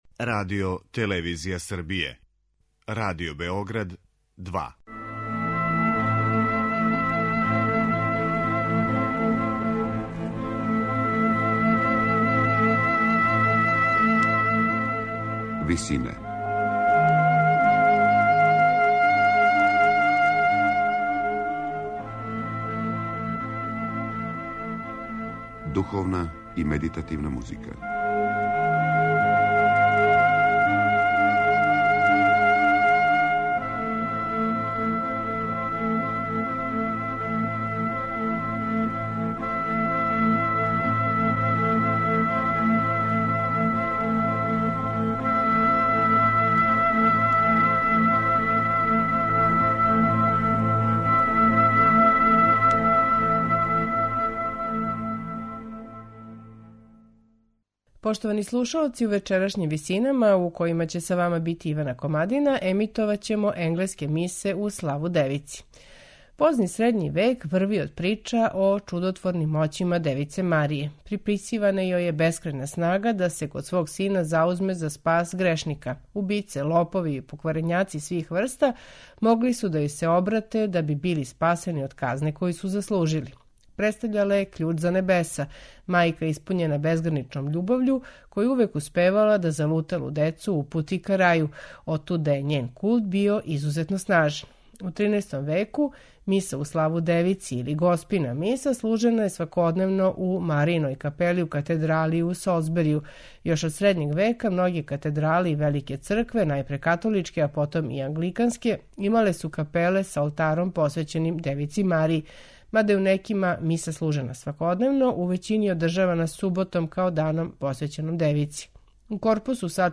Напеви из 13. и 14. века